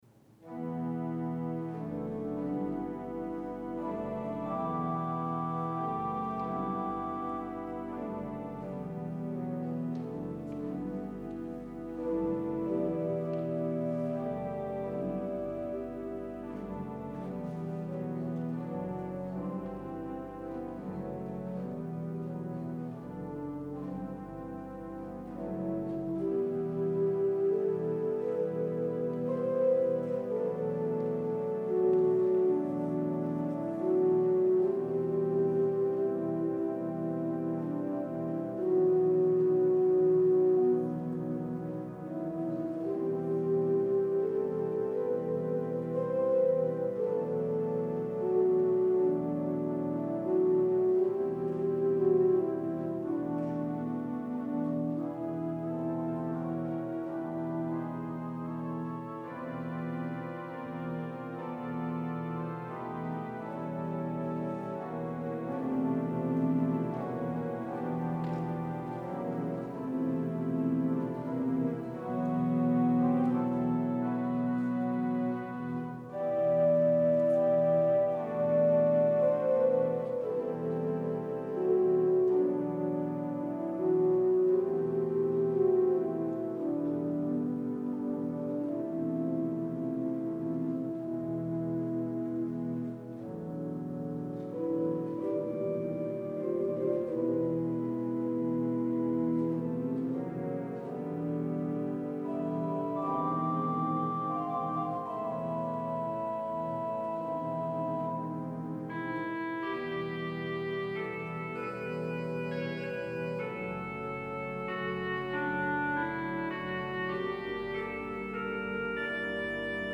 This full service was prerecorded to allow for vacation time for the staff.